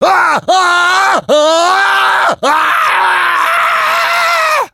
human_panic_1.ogg